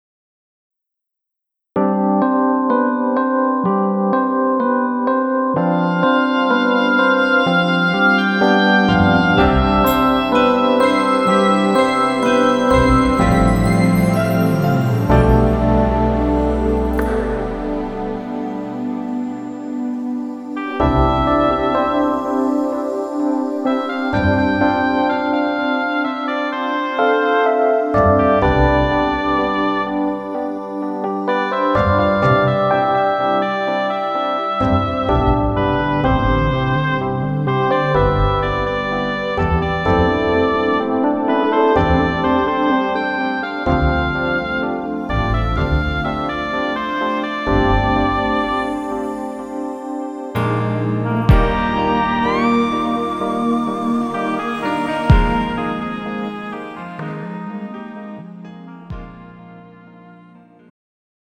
음정 멜로디MR
장르 축가 구분 Pro MR